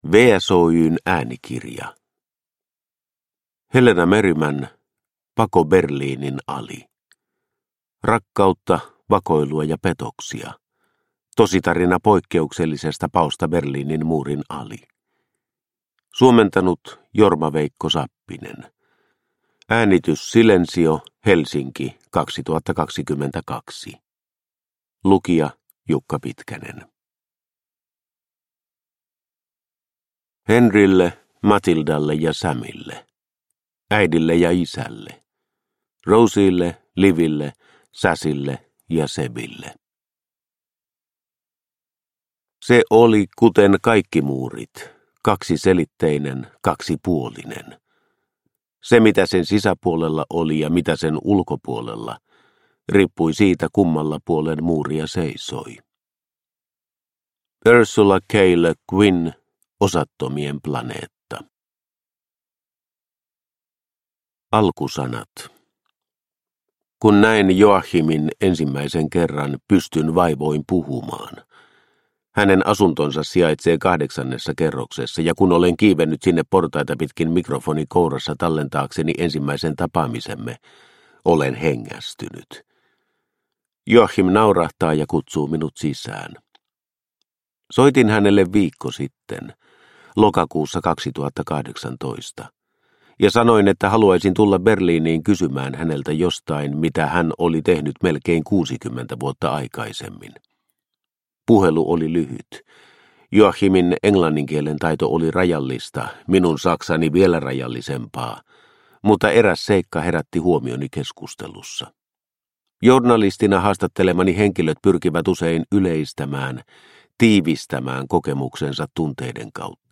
Pako Berliinin ali – Ljudbok – Laddas ner